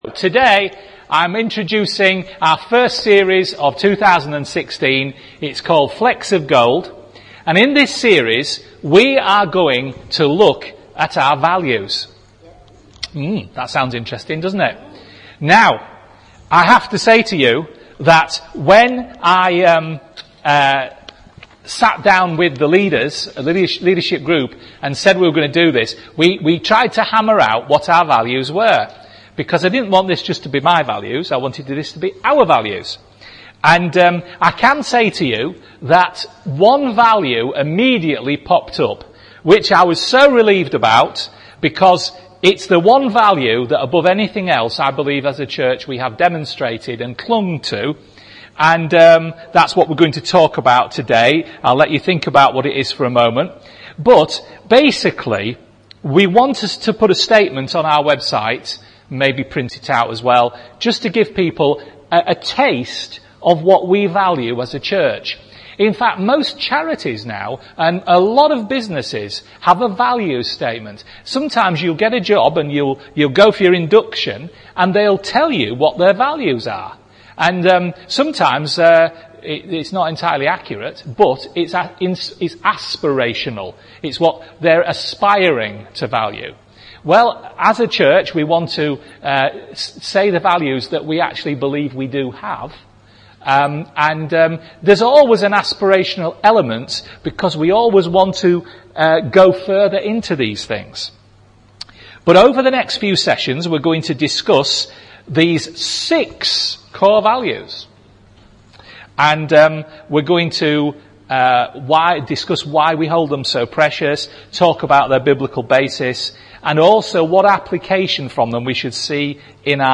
A preaching series that examines our core values as a church, ask ourselves why we hold them so precious, talk about their biblical basis and also what application we should see in our lives and in this church as we implement them.